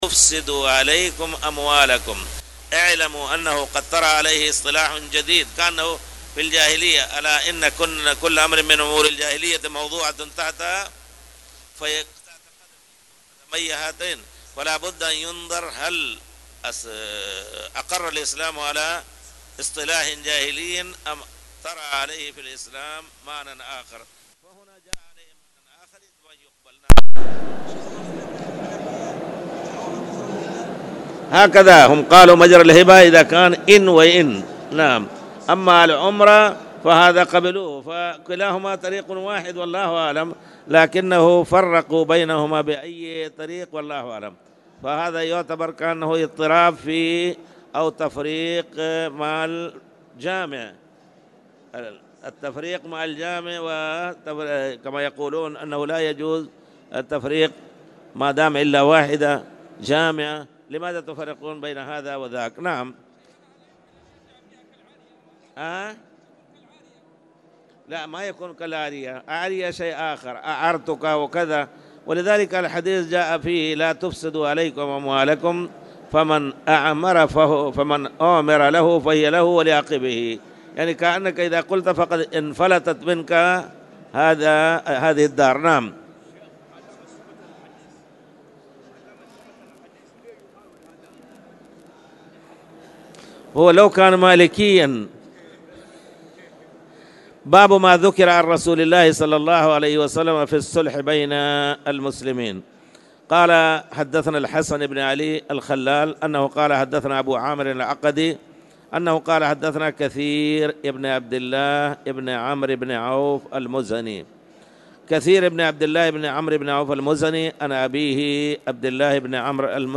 تاريخ النشر ١٨ جمادى الأولى ١٤٣٨ هـ المكان: المسجد الحرام الشيخ